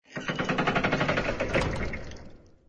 Door2Open3.ogg